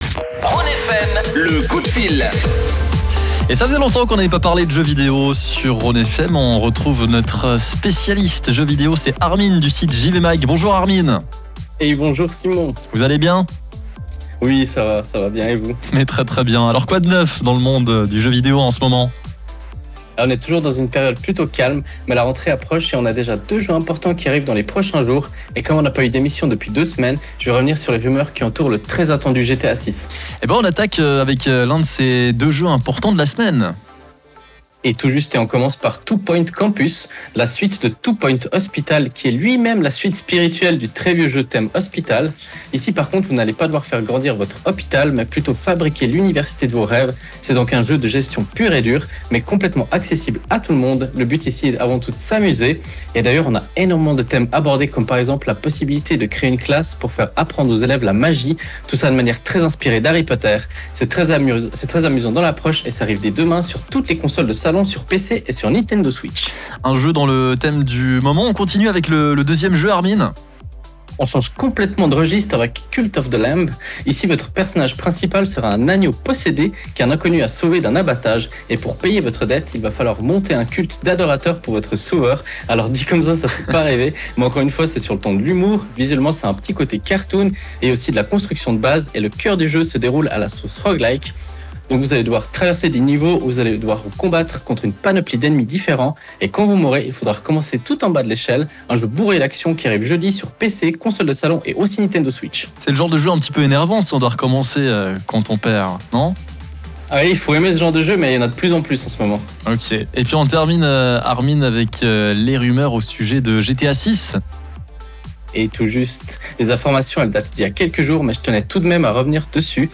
Le live est à réécouter juste en dessus.